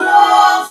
LOVE VOX  -R.wav